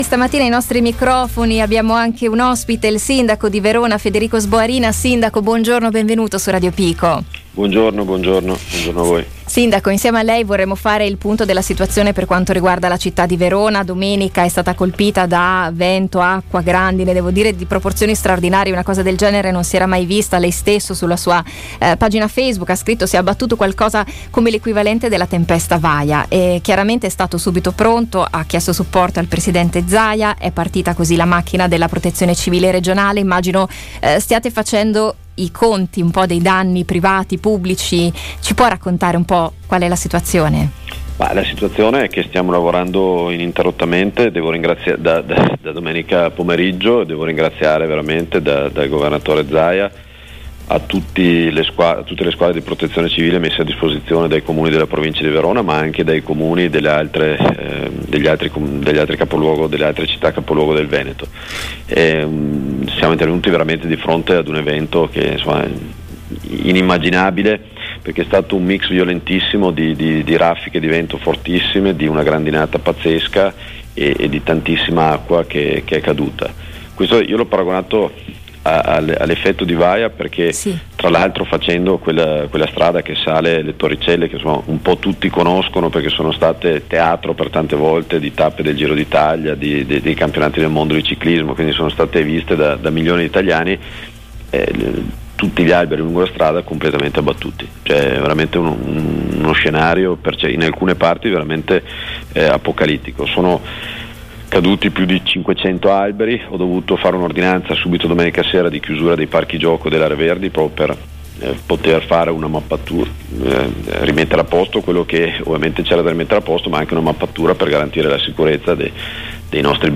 Il sindaco di Verona, Federico Sboarina, è stato ospite telefonico della trasmissione Passepartout, per aggiornarci sulla situazione di Verona. La città, infatti, nella giornata di domenica 23 agosto, è stata letteralmente sommersa di acqua e grandine, per effetto di un violento nubifragio abbattutosi sul centro scaligero e nei dintorni.
Ecco le parole del sindaco, ancora emozionato e riconoscente anche per la telefonata ricevuta al Presidente della Repubblica, Sergio Mattarella: